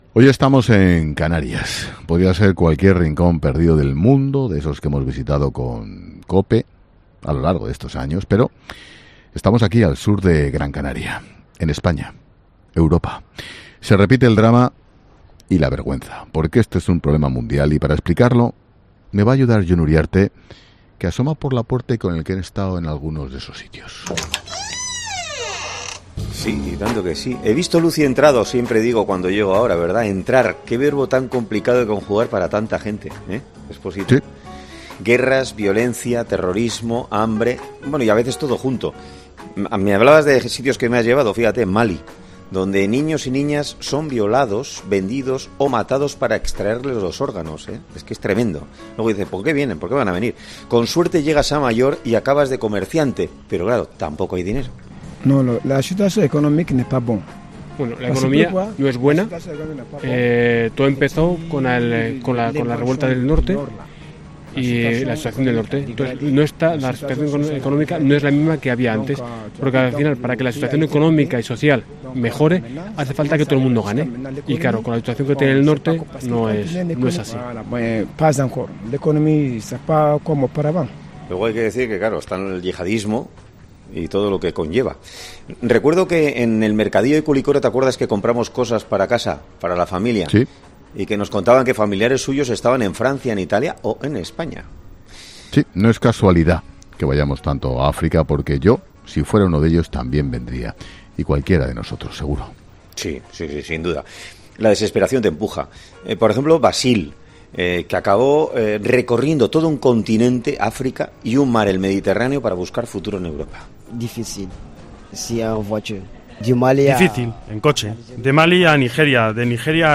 Hoy estamos en Arguineguín, Podría ser cualquier rincón perdido del planeta de esos que hemos visitado con COPE a lo largo de estos años.